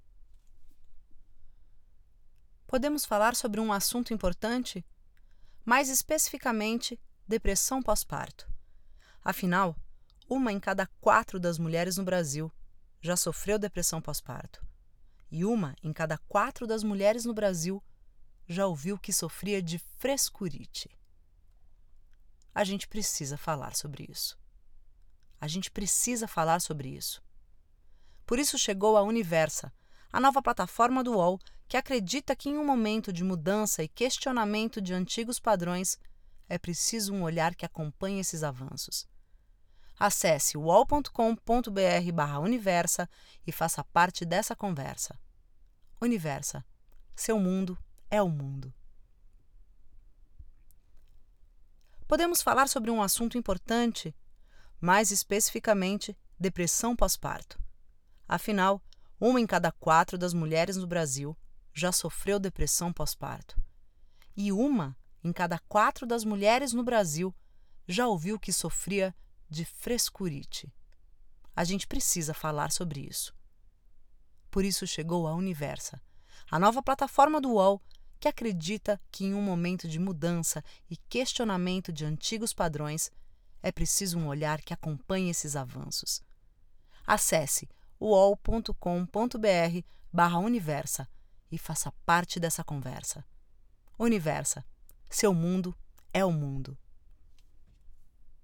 Feminino
Voz Madura 01:38